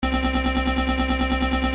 电子合成器3
标签： 140 bpm Electro Loops Synth Loops 296.25 KB wav Key : Unknown
声道立体声